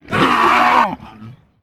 slave_alert2.ogg